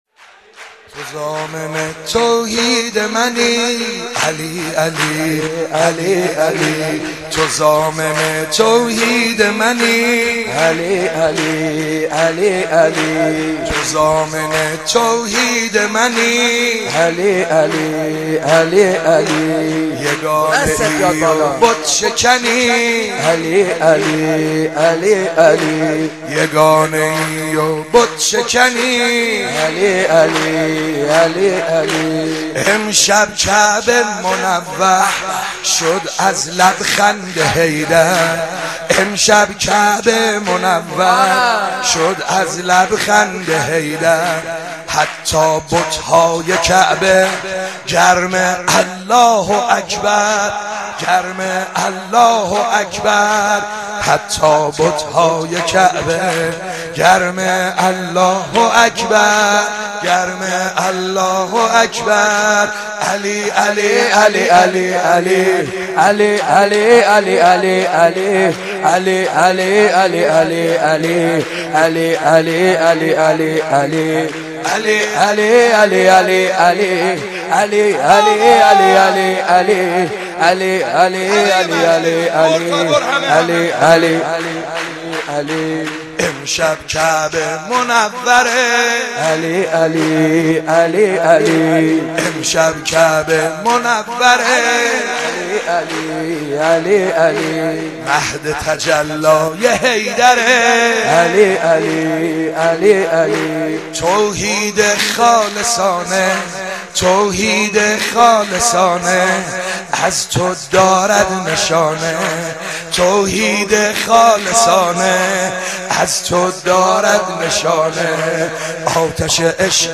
نغمه سرور ( مديحه سرايي ويژه ولادت امام علي عليه السلام ) ...